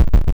Heartbeats.wav